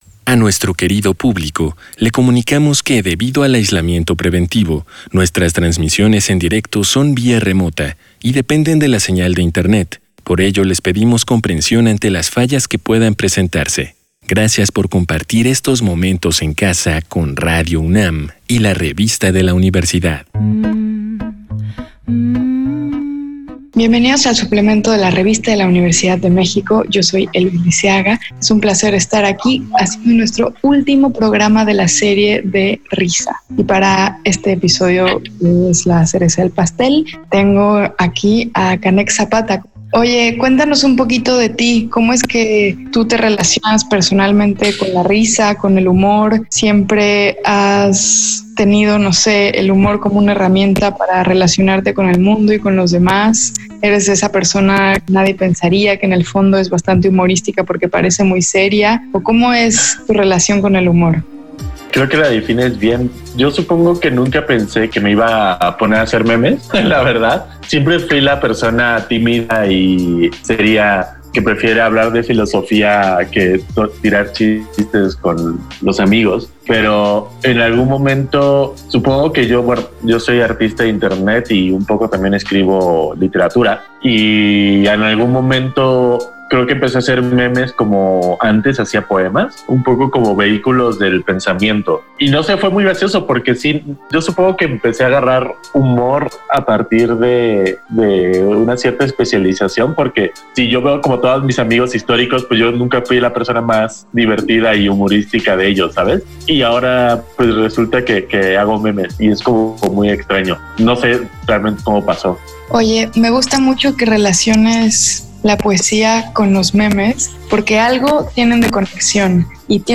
Fue transmitido el jueves 29 de octubre de 2020 por el 96.1 FM.